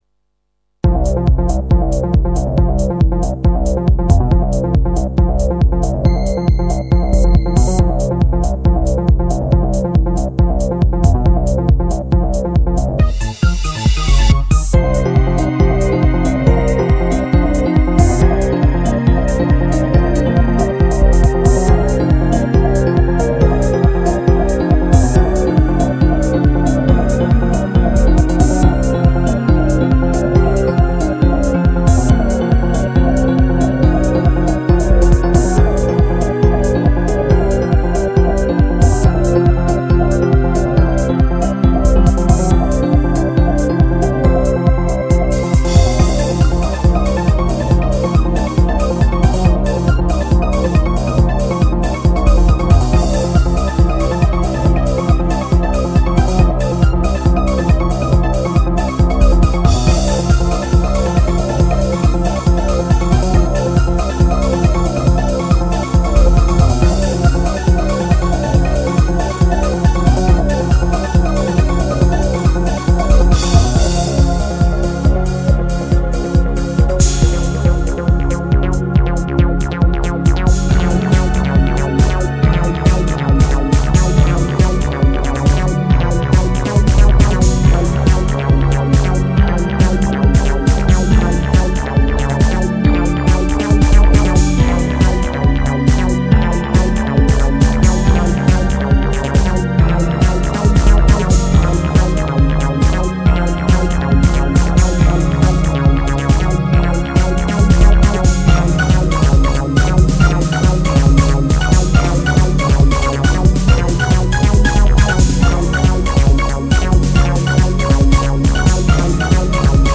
・シューティングっぽいもの第2弾